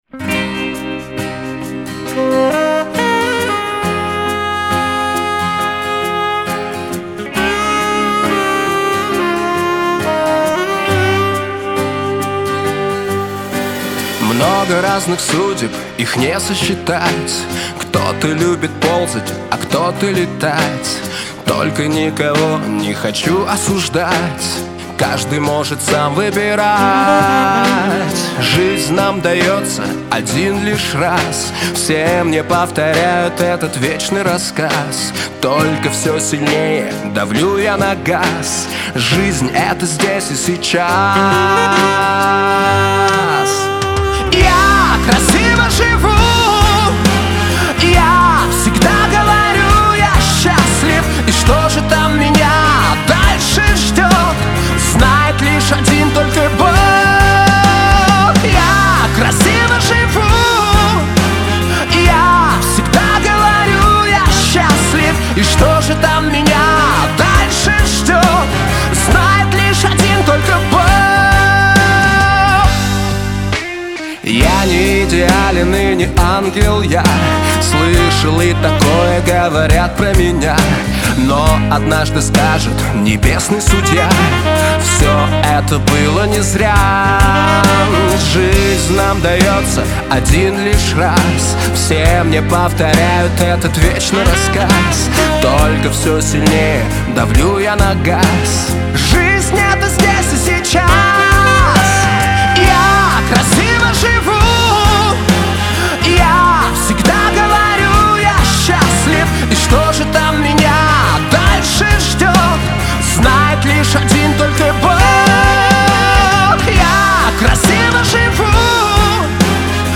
Трек размещён в разделе Русские песни / Поп.